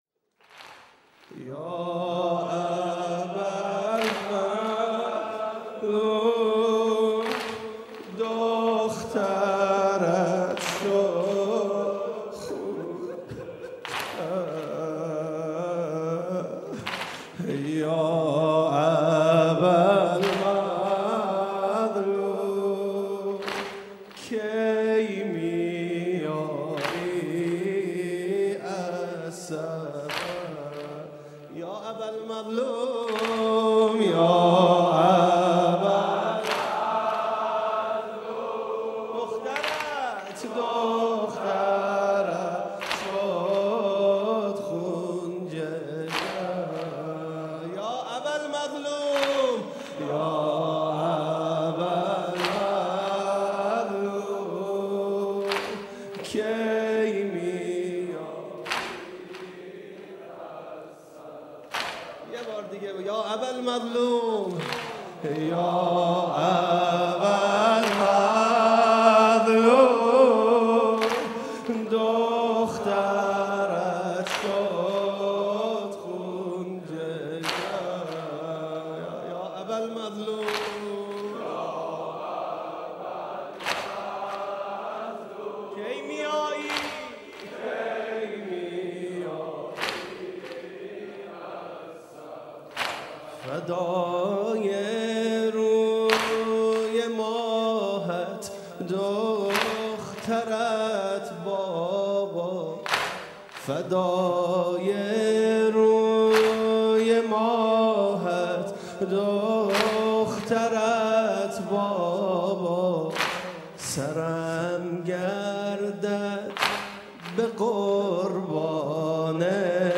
گلچين محرم 95 - واحد - یا ابا المظلوم